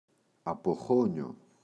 αποχώνιο, το [apo’xoɲo]